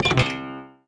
Catapult Sound Effect
catapult-1.mp3